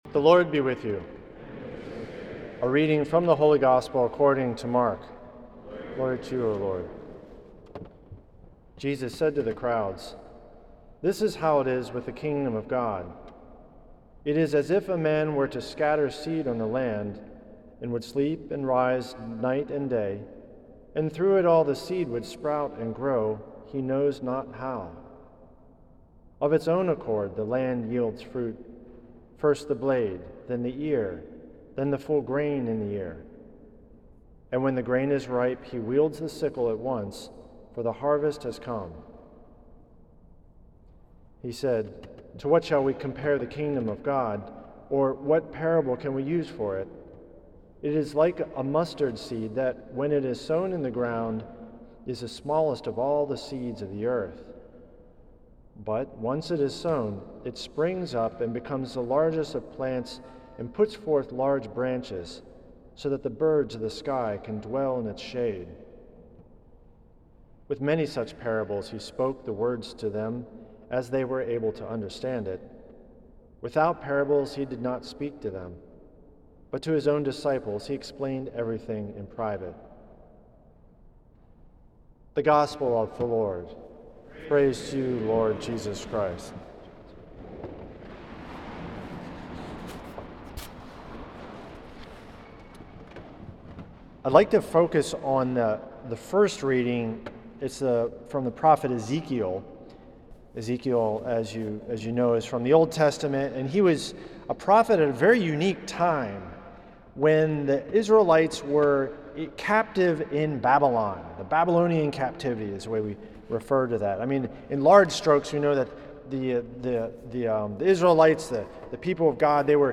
at St. Patrick’s Old Cathedral in NYC.